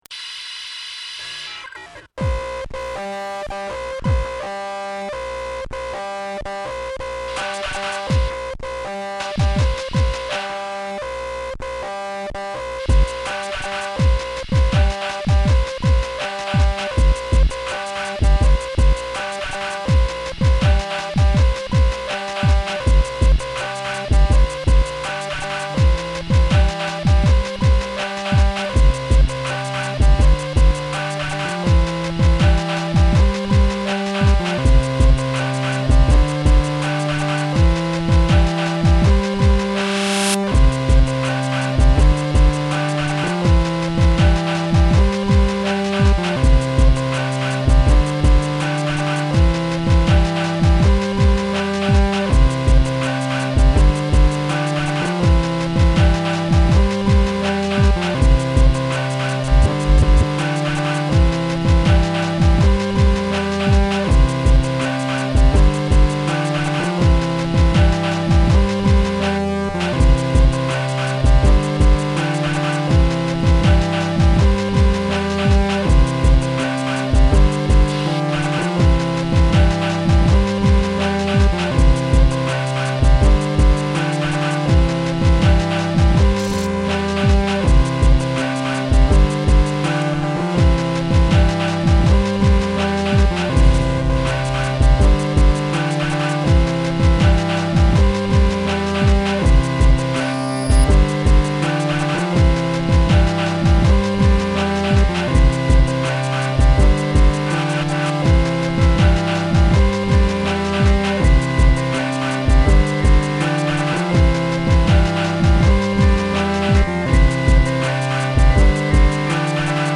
All made with a combo of records, Yamaha SU200 sampler, Casio MT68, Yamaha PSS 470, and Cakewalk Home Studio via a M-Audio Delta 44.